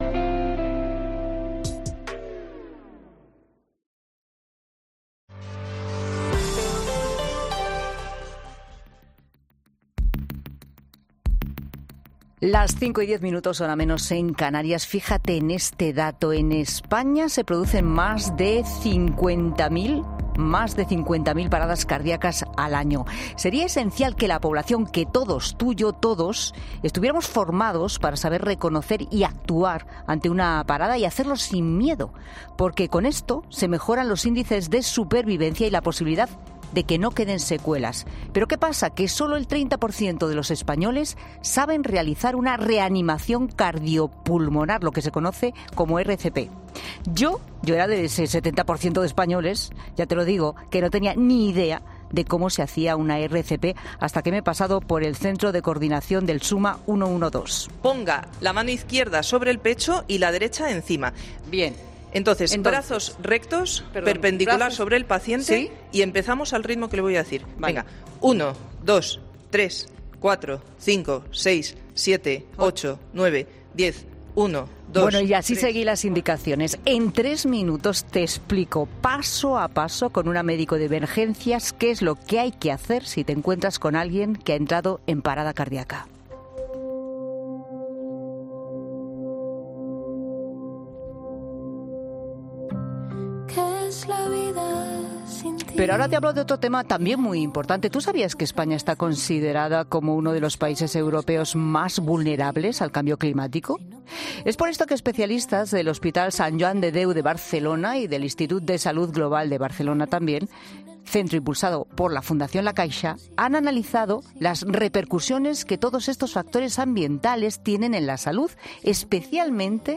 El equipo de 'La Tarde' se ha trasladado hasta el centro de Coordinación del SUMMA 112, donde ha entendido la importancia de conocer y saber...